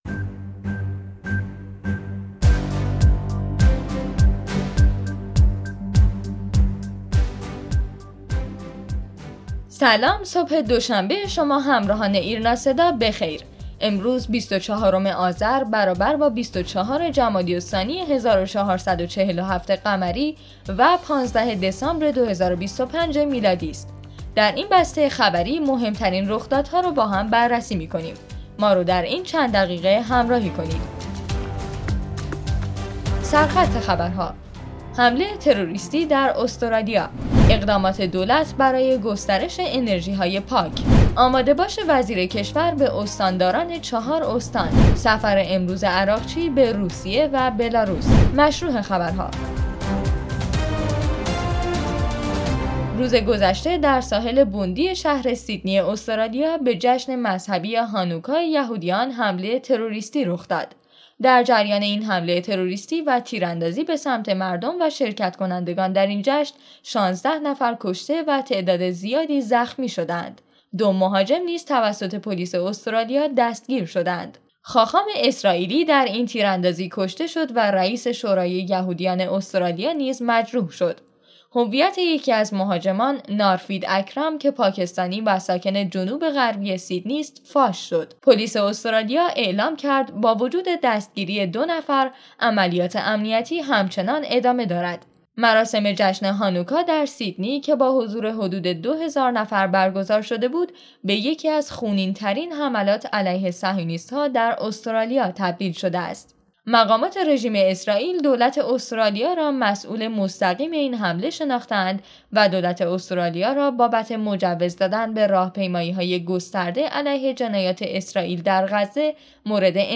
بسته خبری_تحلیلی "ایرناصدا" دوشنبه بیست و چهارم آذرماه